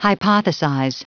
Prononciation du mot hypothesize en anglais (fichier audio)
Prononciation du mot : hypothesize